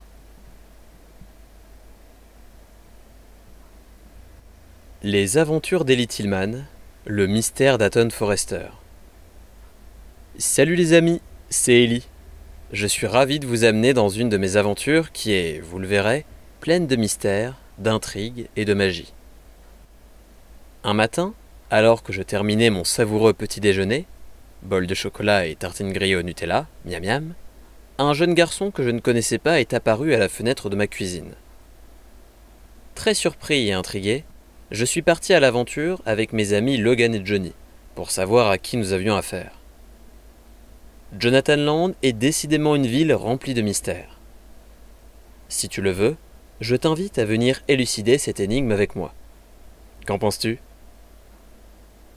Résumé audio